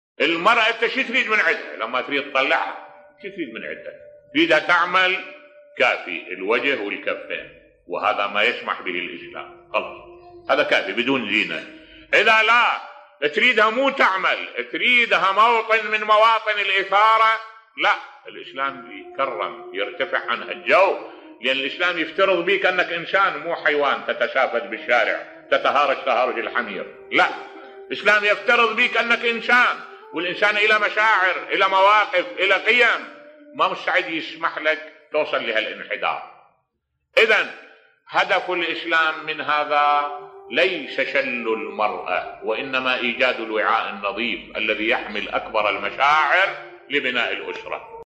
ملف صوتی لماذا أوجب الله الحجاب على المرأة بصوت الشيخ الدكتور أحمد الوائلي